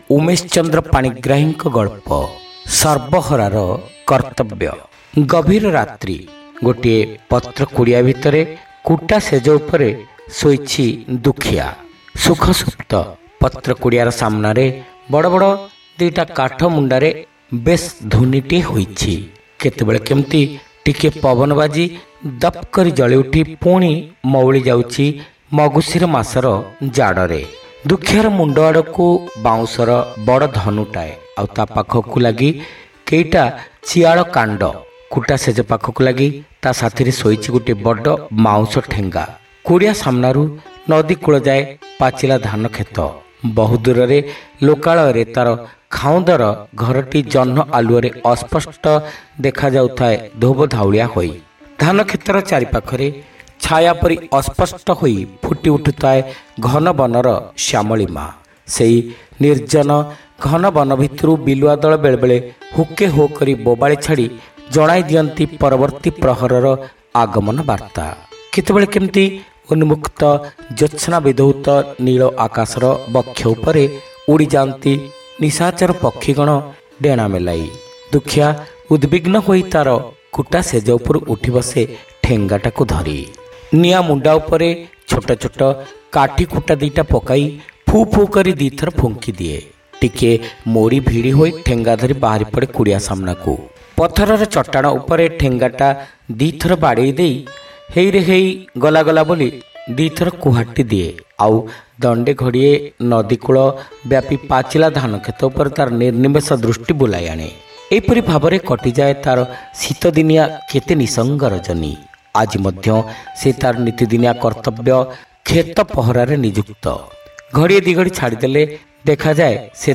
Audio Story : Sarbaharara Kartabya